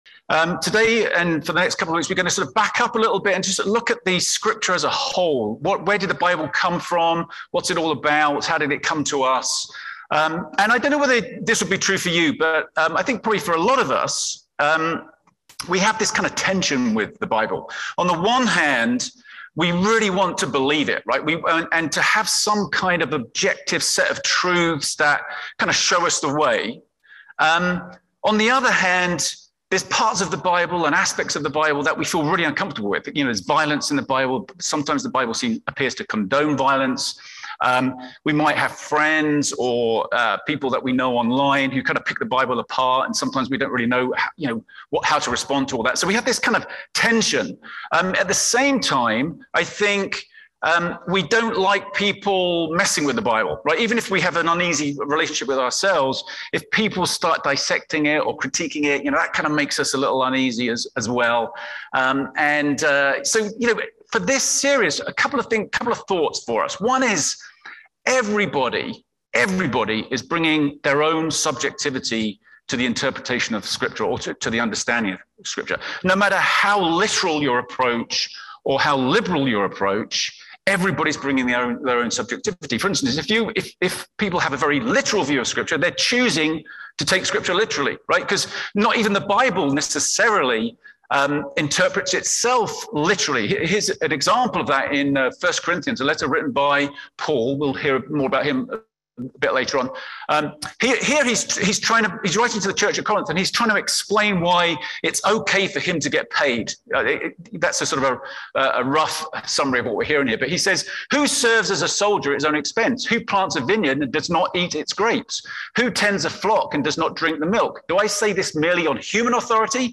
A message from the series "Next Steps."